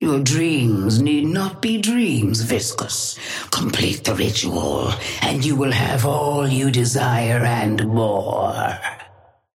Sapphire Flame voice line - Your dreams need not be dreams, Viscous.
Patron_female_ally_viscous_start_09.mp3